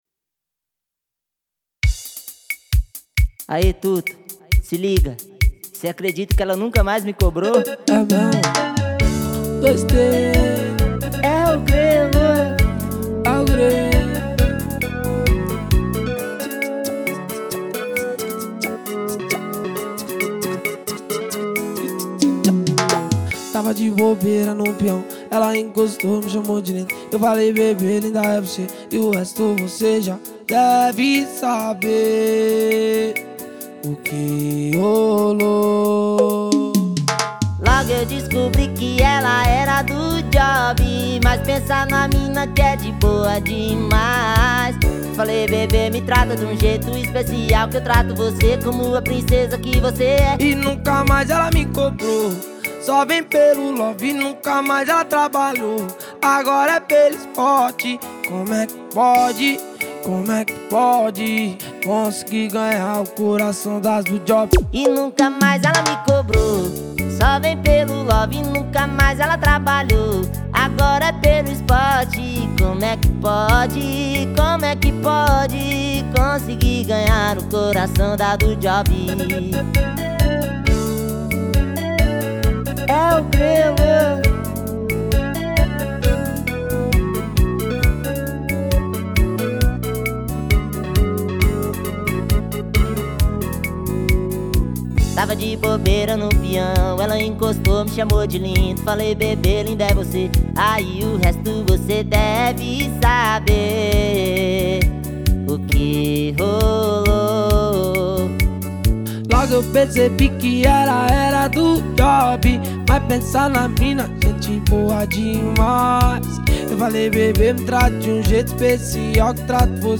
2025-04-08 20:29:48 Gênero: Forró Views